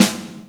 Index of /KonaSportApp/konaapp/assets/exercise/audio/metronome
snare44.wav